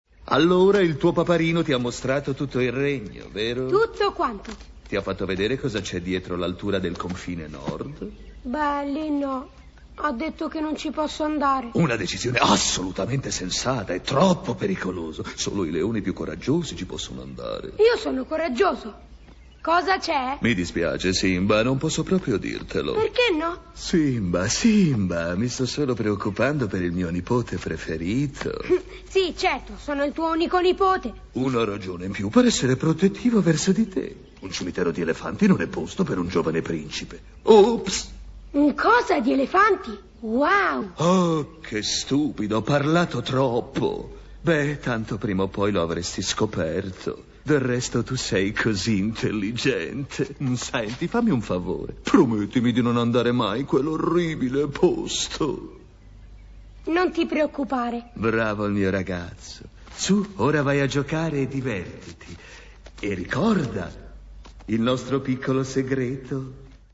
voce di Tullio Solenghi nel film d'animazione "Il Re Leone", in cui doppia Scar.